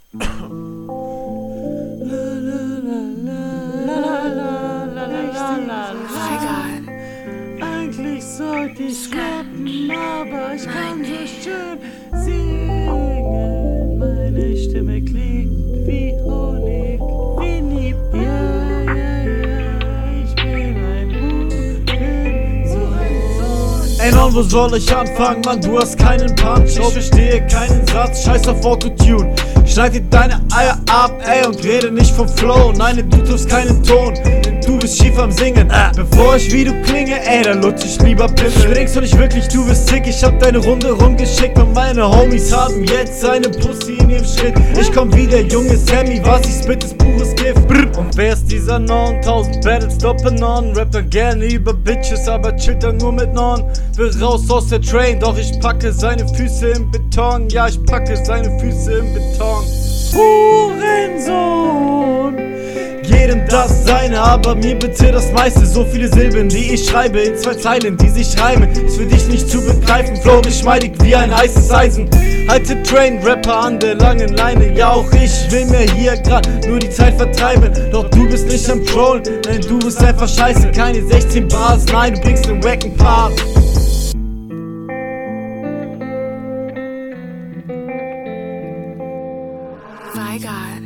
Mir gefällt der schiefe Gesang am Anfang und bei „Hurensohn“.